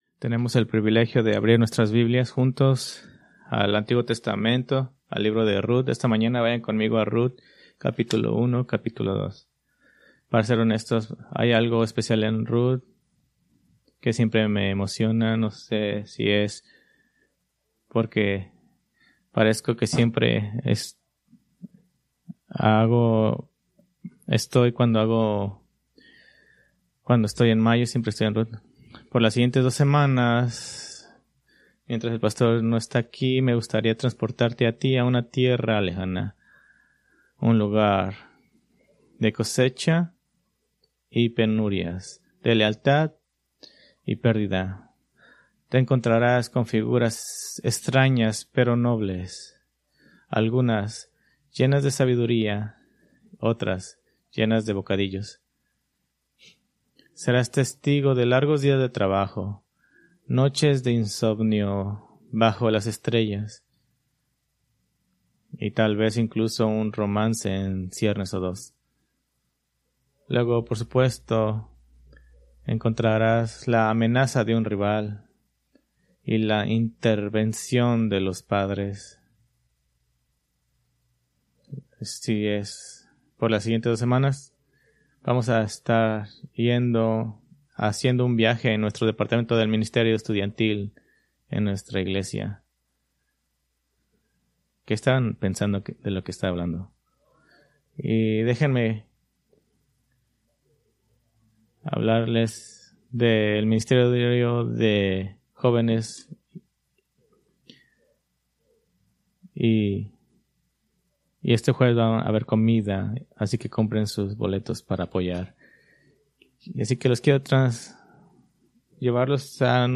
Preached May 18, 2025 from Rut 1-2